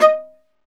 Index of /90_sSampleCDs/Roland - String Master Series/STR_Viola Solo/STR_Vla1 _ marc